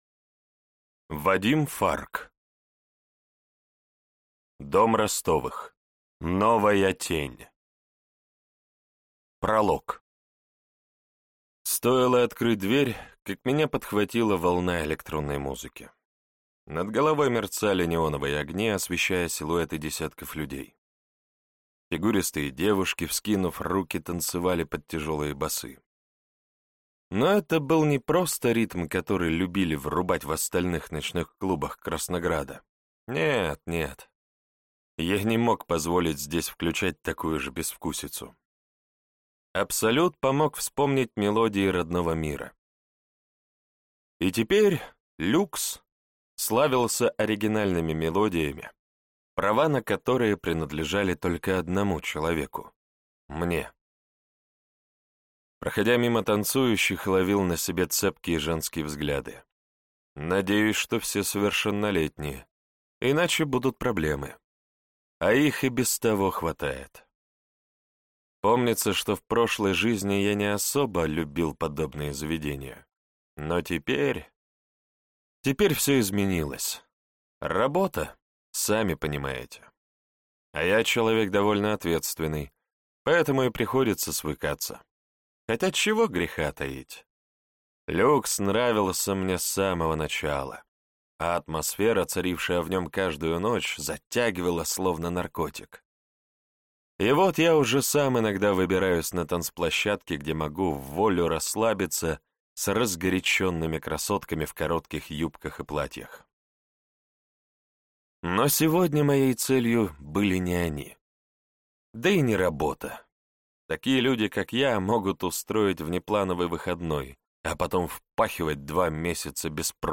Аудиокнига Дом Ростовых. Новая Тень | Библиотека аудиокниг